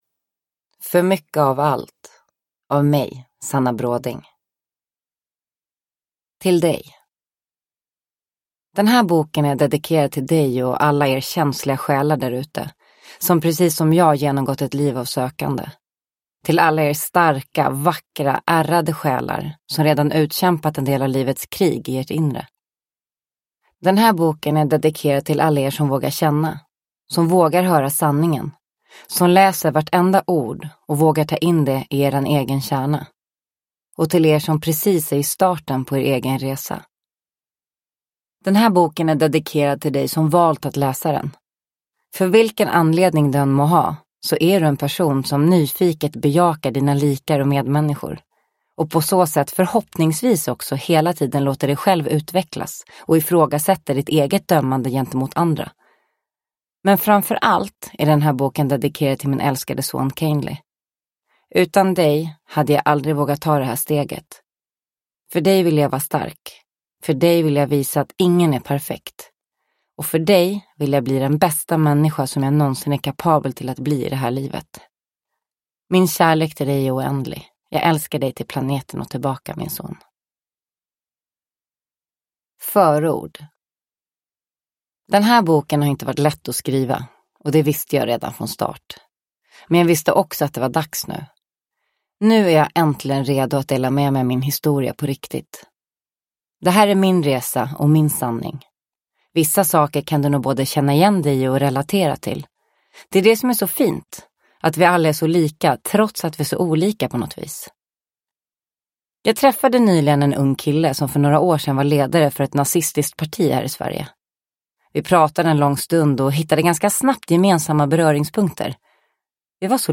Uppläsare: Sanna Bråding